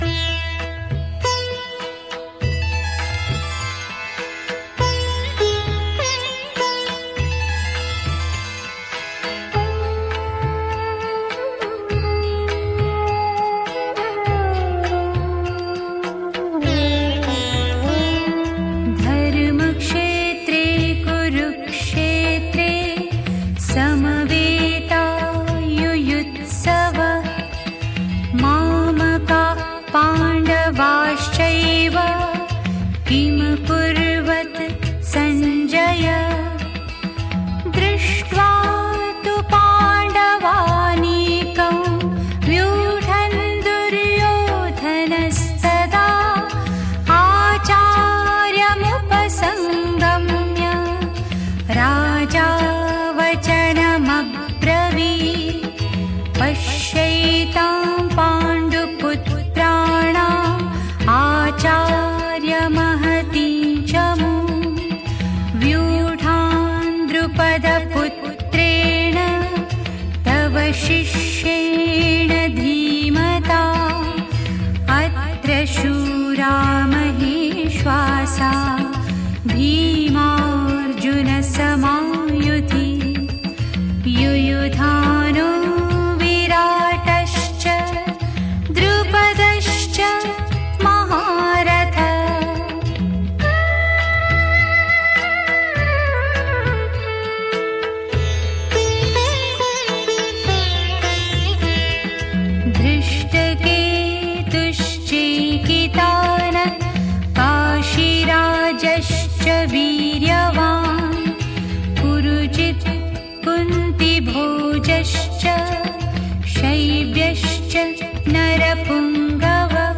Let’s listen to the start of the Bhagavad Gita. This one is sung by Anuradha Paudwal. Each line of the Gita consists of eight syllables.